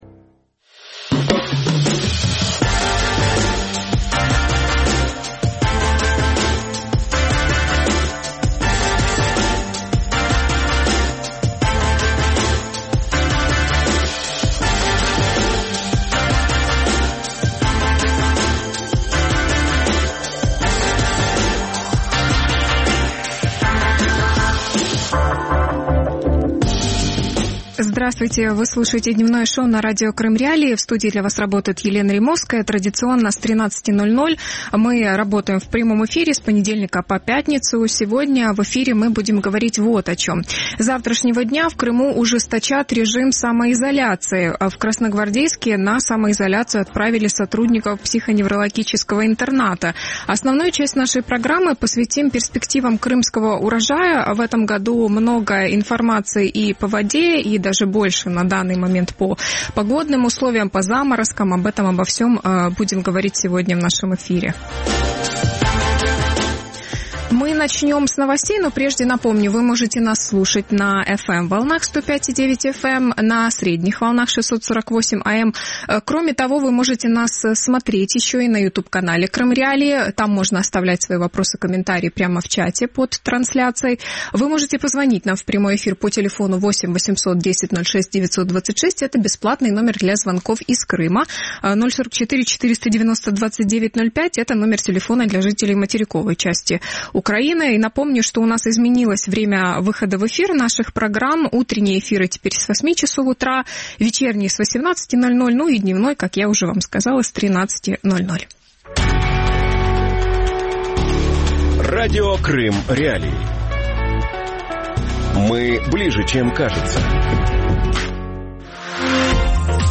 Урожай и цены в Крыму | Дневное ток-шоу